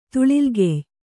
♪ tuḷigey